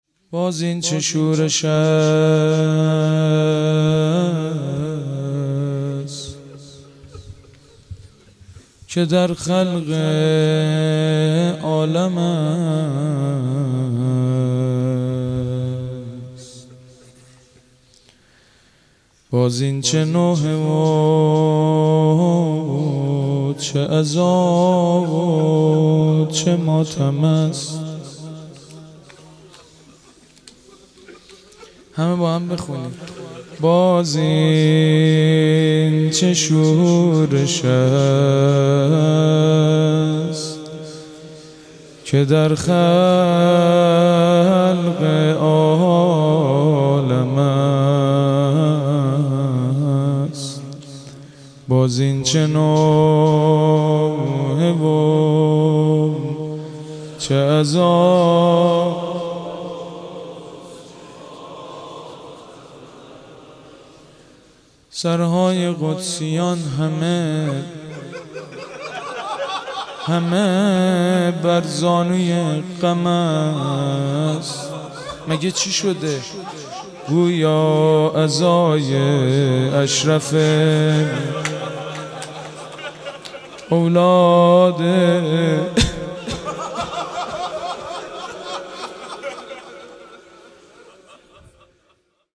دفتر زیارتی نیایش سیر قزوین- مداحی بنی فاطمه